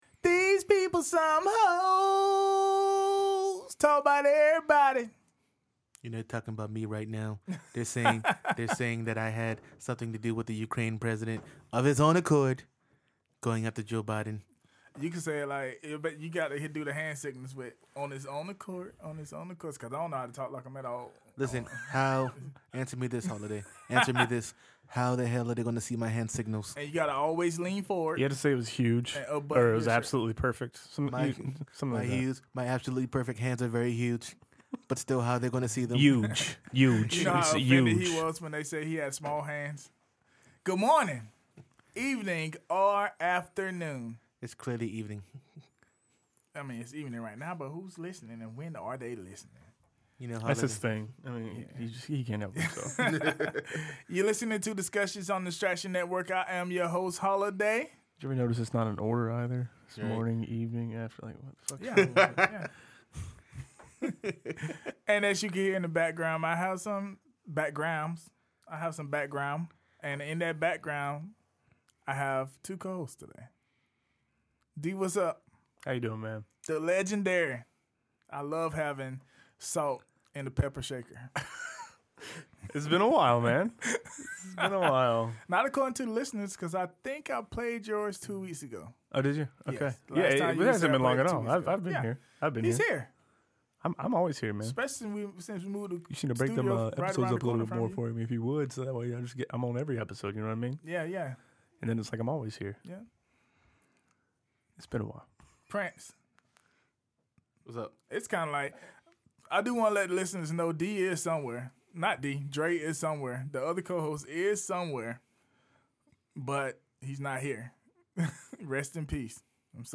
*Cue the DJ Mixes*.